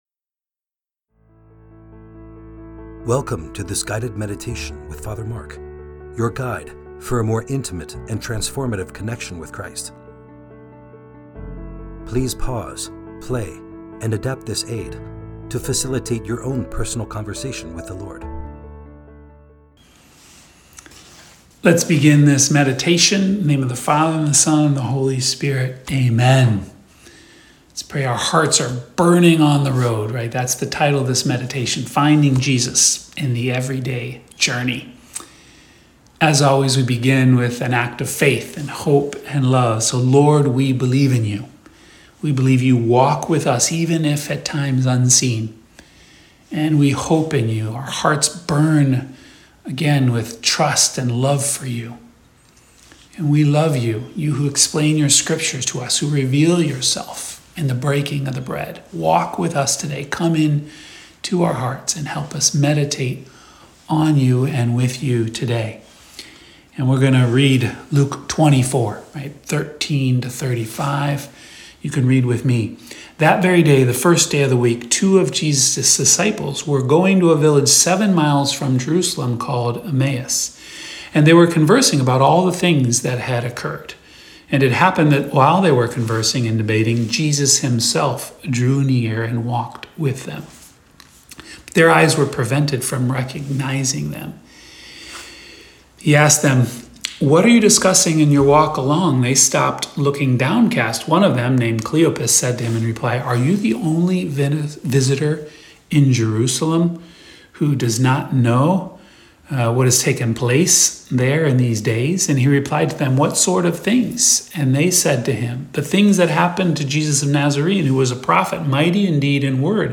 Friday Meditation